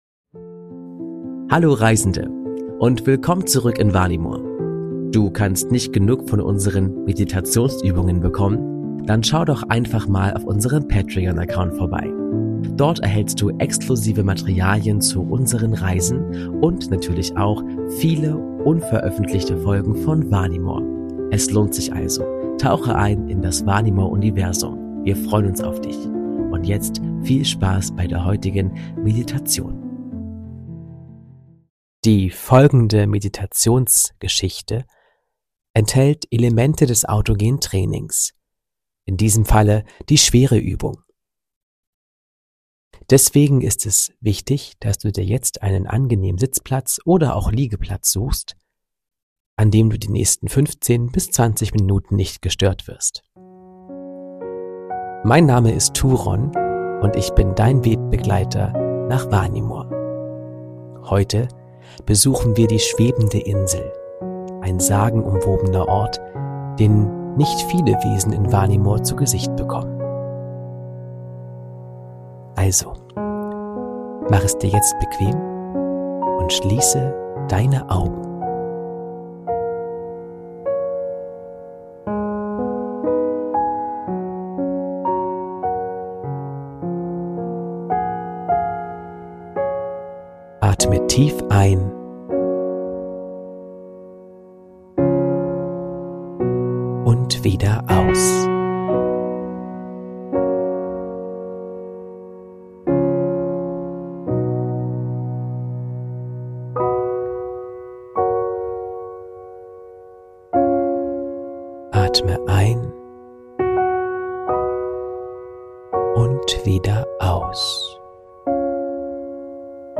Autogenes Training/ Entspannungsgeschichte: Die schwebende Insel ~ Vanimor - Seele des Friedens Podcast